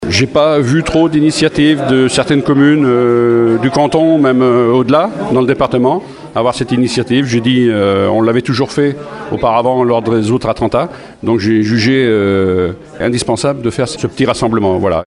Un rassemblement voulu par le maire Guy Proteau qui regrette la faible mobilisation de ses homologues dans le département :